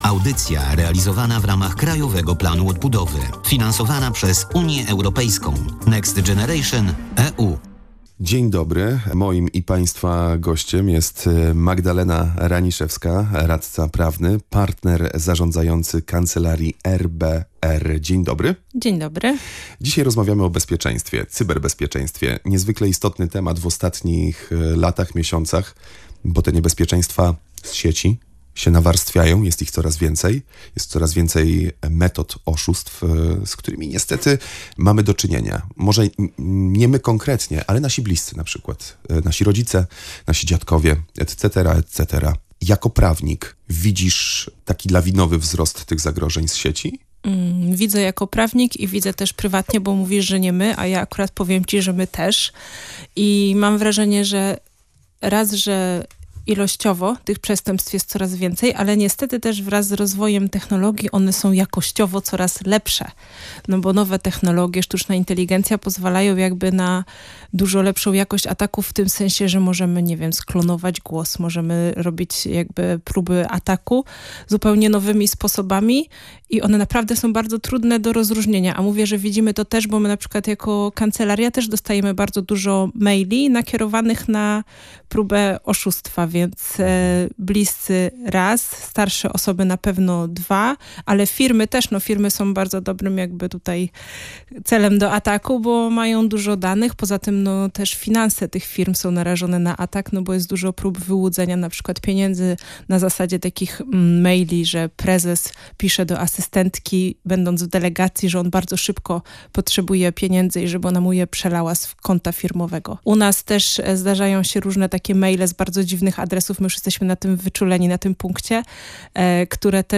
W audycji „W Cyfrowym Świecie” radziła